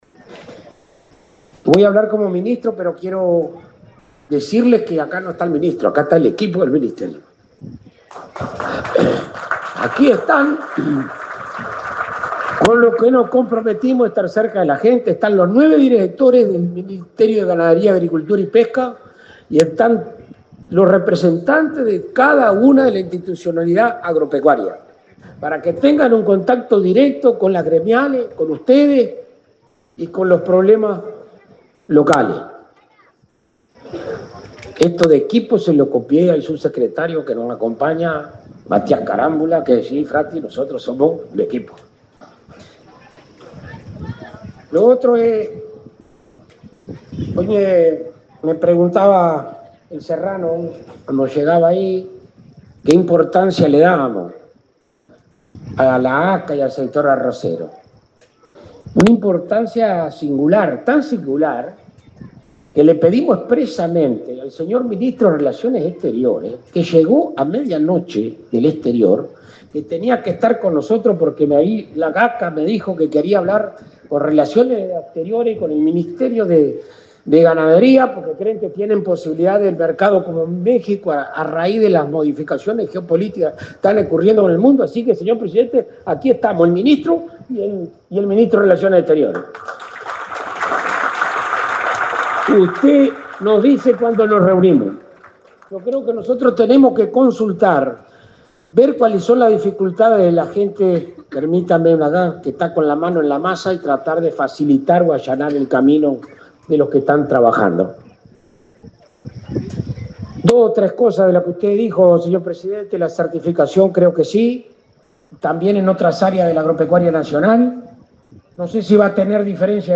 Palabras del ministro de Ganadería, Alfredo Fratti
Palabras del ministro de Ganadería, Alfredo Fratti 13/03/2025 Compartir Facebook X Copiar enlace WhatsApp LinkedIn El ingreso a nuevos mercados y el Plan Nacional de Aguas fueron dos de los temas abordados por el ministro de Ganadería, Alfredo Fratti, durante su alocución en el acto de inauguración de la cosecha de arroz 2025, realizado este jueves 13 en el departamento de Treinta y Tres.